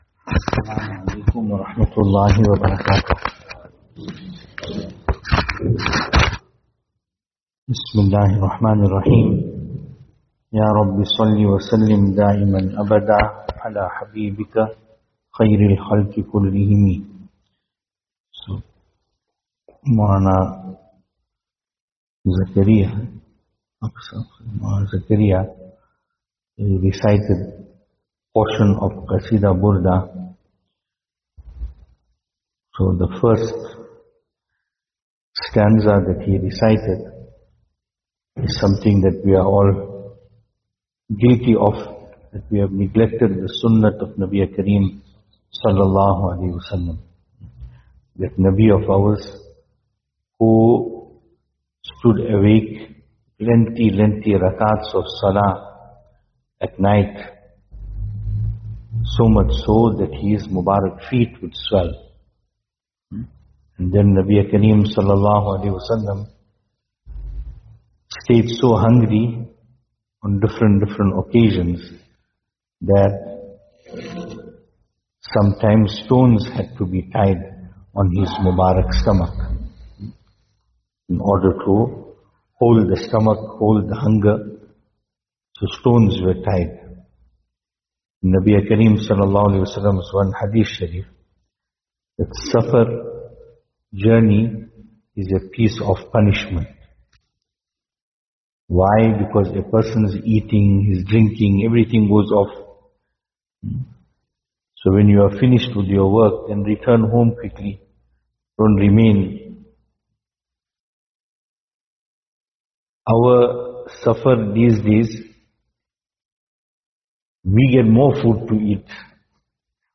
Programme from Marlboro Musjid